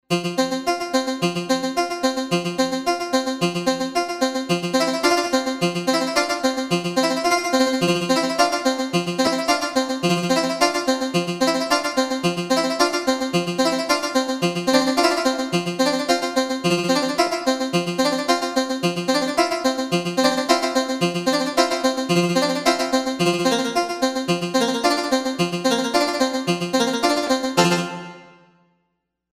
I think the humble R3 can come close to the original.